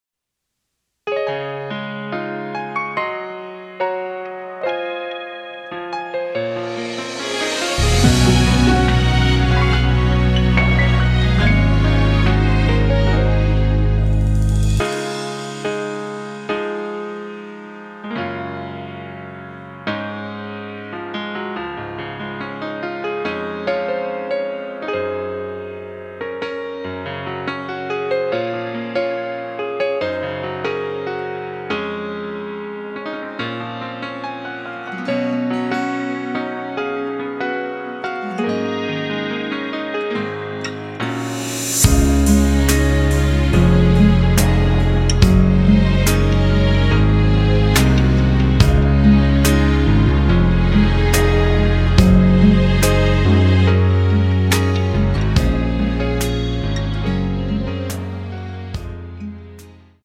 Db
앞부분30초, 뒷부분30초씩 편집해서 올려 드리고 있습니다.